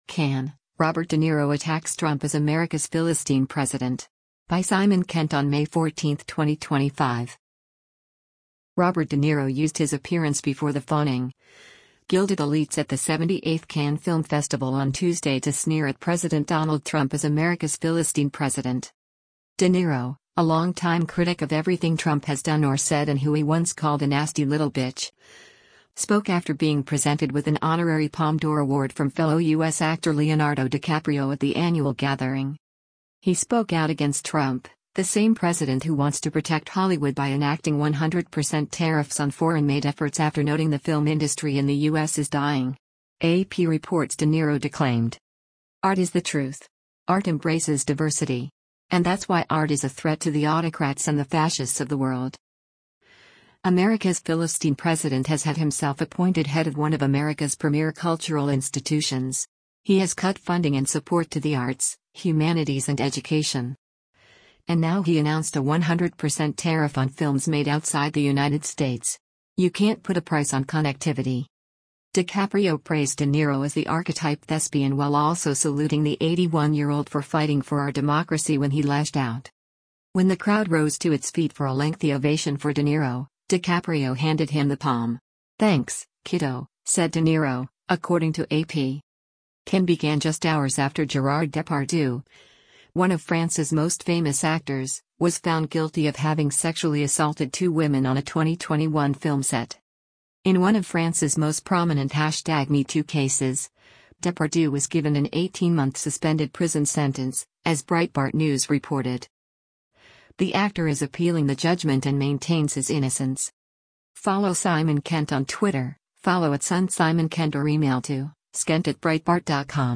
Robert De Niro receives the Honorary Palme d’Or from U.S. actor Leonardo DiCaprio and the two exchange cheek-kisses during the opening of the 78th edition of the Cannes Film Festival in Cannes, southern France, on May 13, 2025.
When the crowd rose to its feet for a lengthy ovation for De Niro, DiCaprio handed him the Palme.